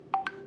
yay.wav